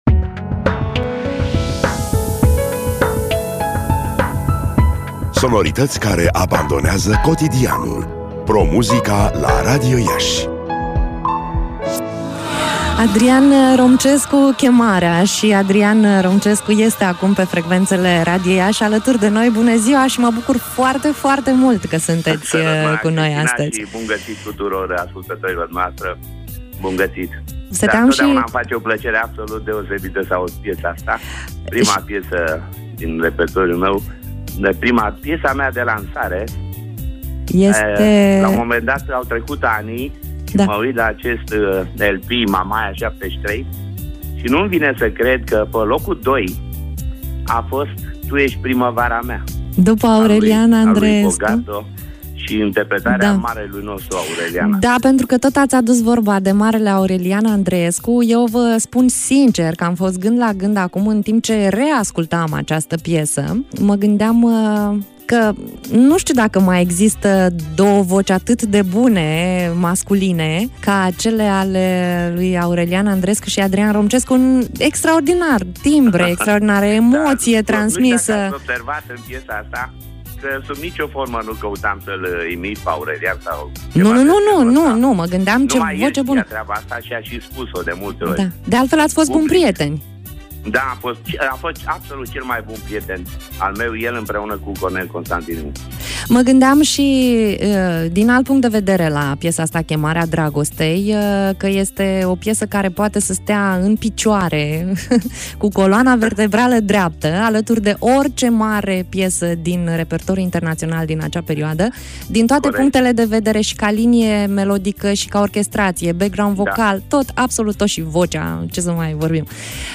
interviu-adrian-romcescu.mp3